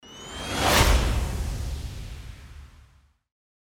FX-1616-WIPE
FX-1616-WIPE.mp3